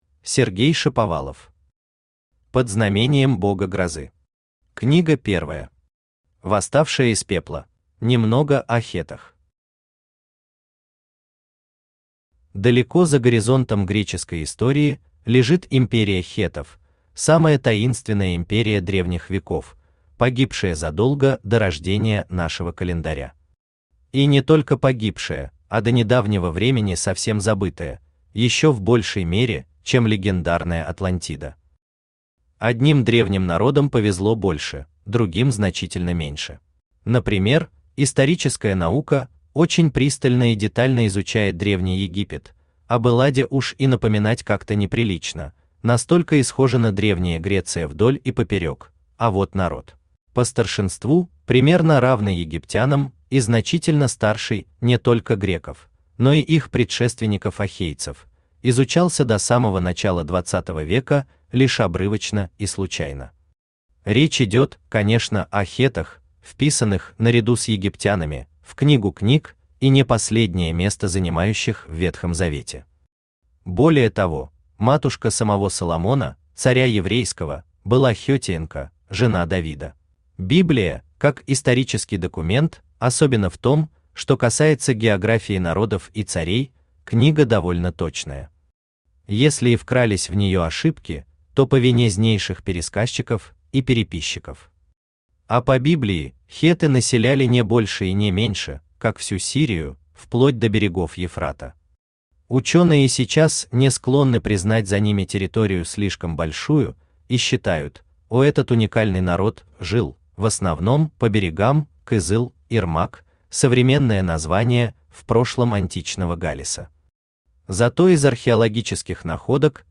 Восставшая из пепла Автор Сергей Анатольевич Шаповалов Читает аудиокнигу Авточтец ЛитРес.